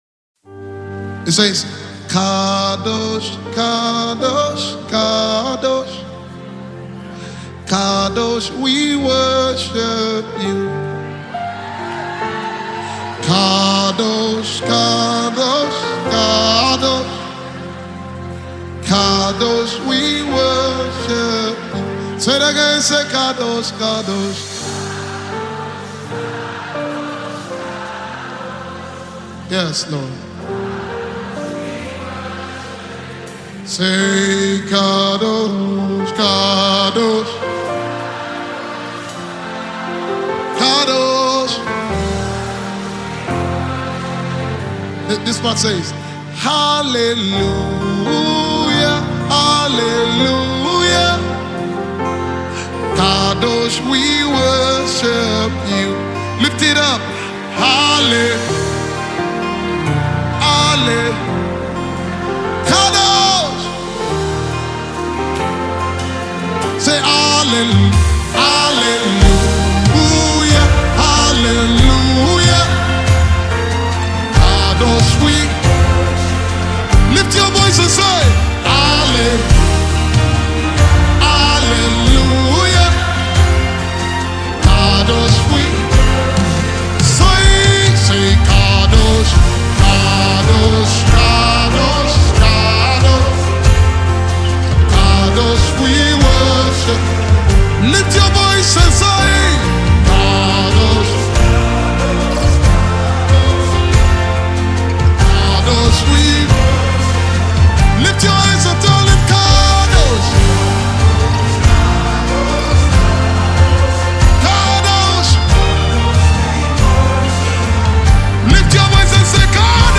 Finest gospel music singer
an amazing, powerful, spiritual and glorious melody